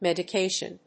/mèdəkéɪʃən(米国英語), ˌmedʌˈkeɪʃʌn(英国英語)/